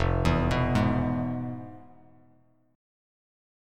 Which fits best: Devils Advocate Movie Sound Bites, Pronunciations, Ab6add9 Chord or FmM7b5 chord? FmM7b5 chord